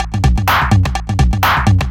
DS 126-BPM A07.wav